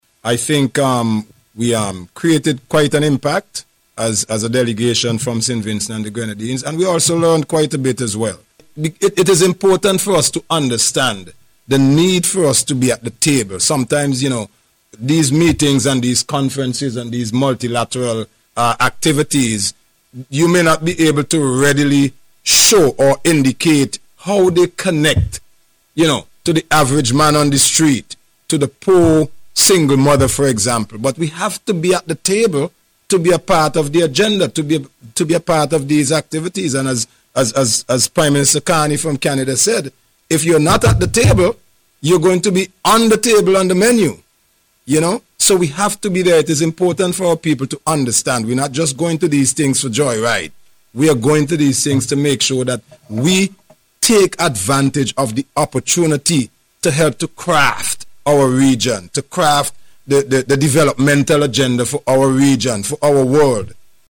Speaking at a News Conference, hosted by Prime Minister Dr. Hon. Godwin Friday on Tuesday, he stressed the importance of attendance at high-level meetings of this nature.